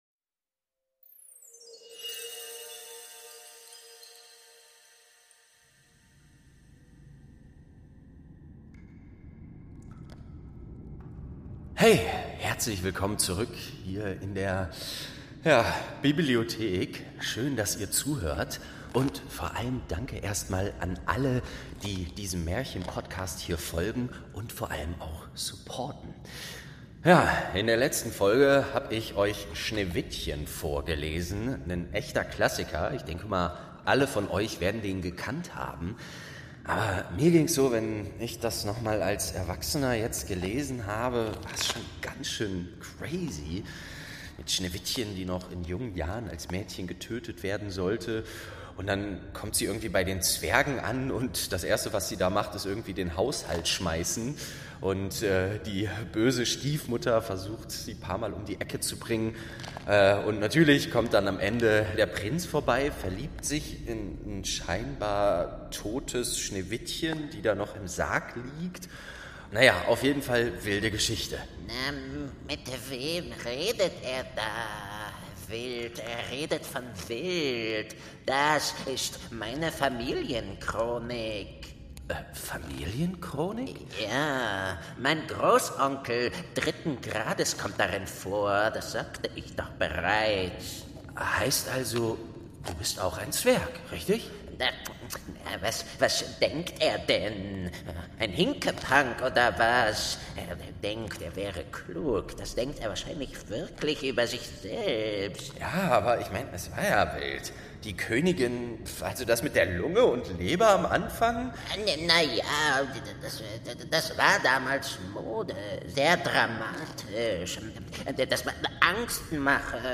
6. König Drosselbart| Staffel 2 ~ Märchen aus der verschollenen Bibliothek - Ein Hörspiel Podcast